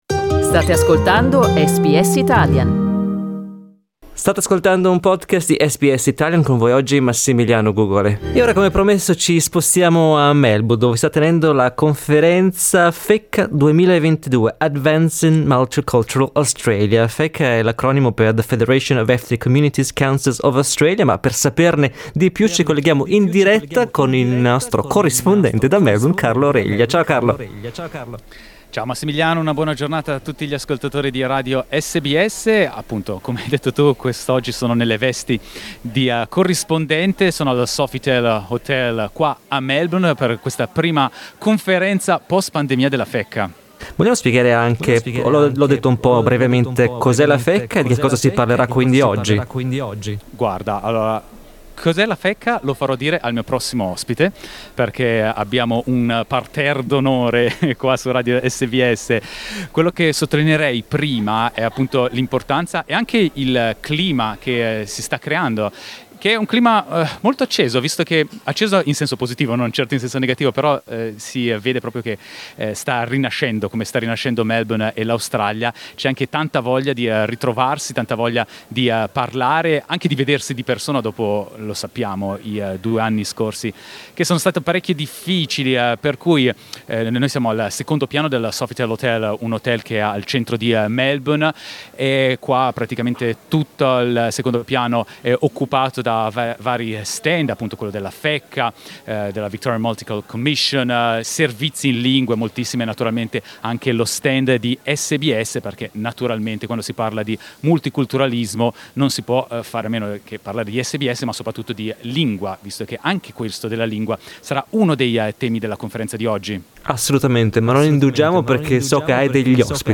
Ascolta qui il collegamento nella giornata inaugurale della conferenza della Fecca